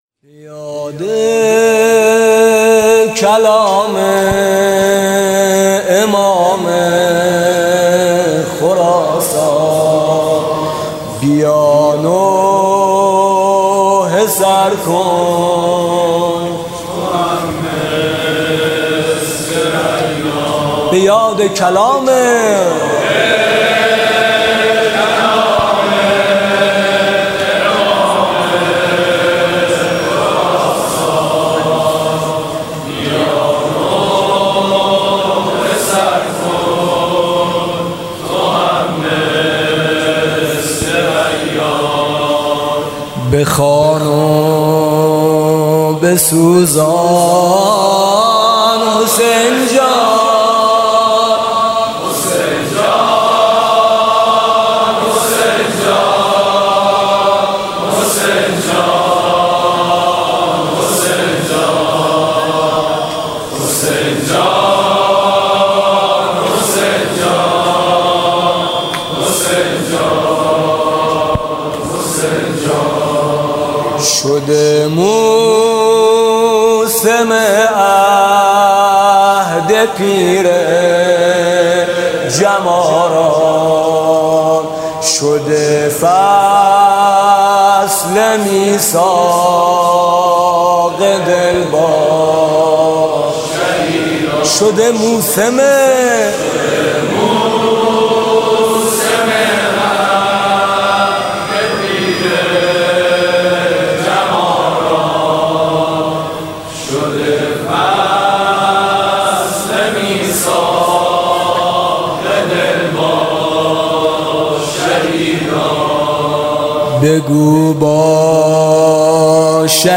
محرم 95
دم آغازین